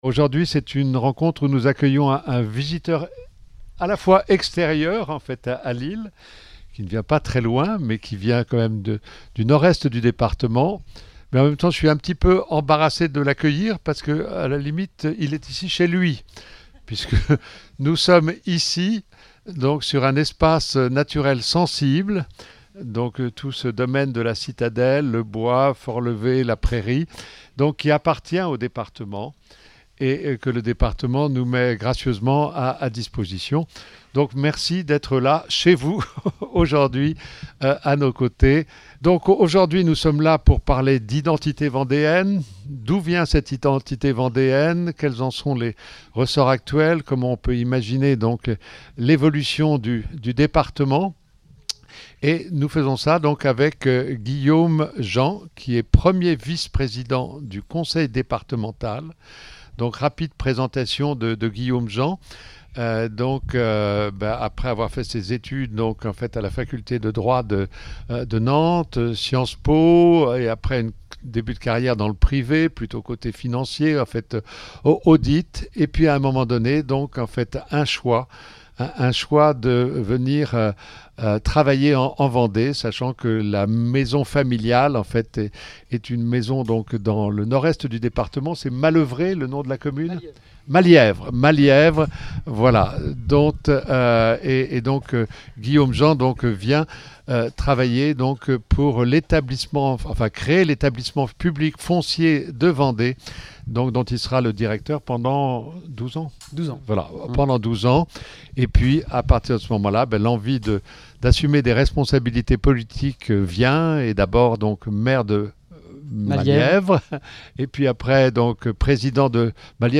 Le vendredi 22 août 2025, Guillaume Jean, premier vice-président du Conseil Départemental de la Vendée, a été invité à venir partager sa vision de l’identité vendéenne, en revenant sur ses fondements et ses particularités.
La rencontre s’est poursuivie par un échange avec le public, qui a pu lui poser ses questions.